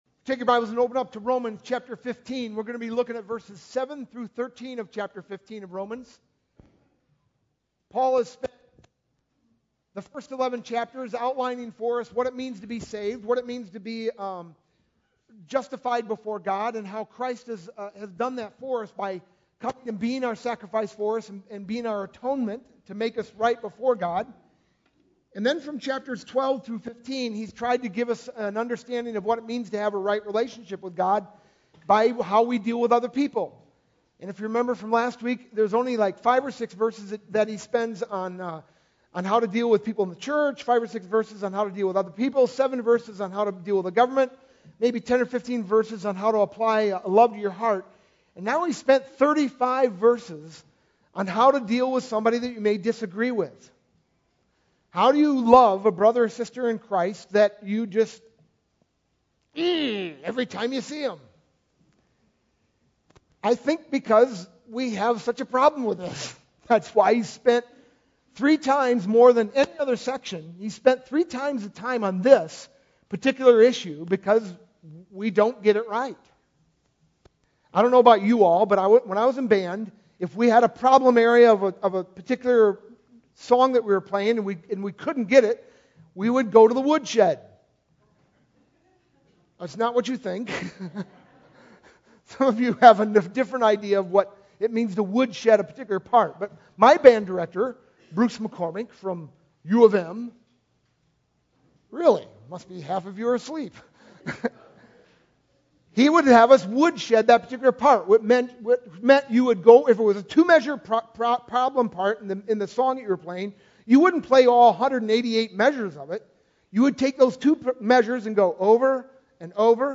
Sunday, February 26th, 2012 – Freedom Sunday
sermon-2-26-12.mp3